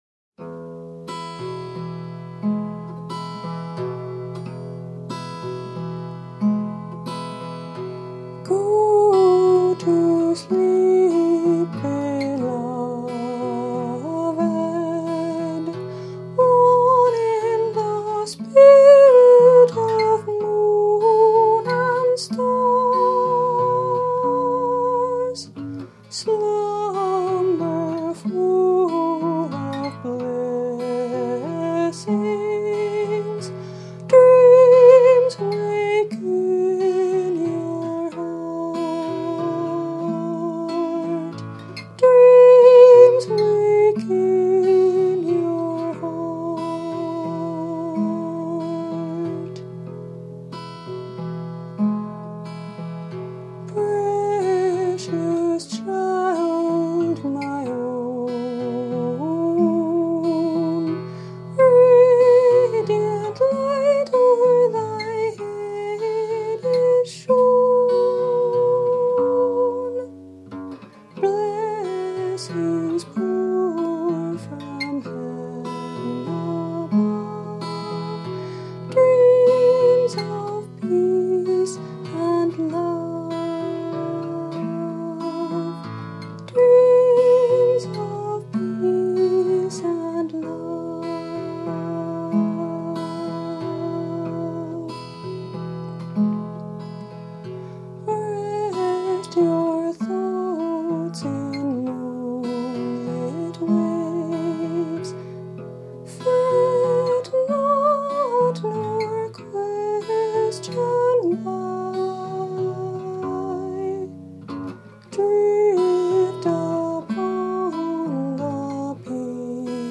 Seagull Excursion Folk Acoustic Guitar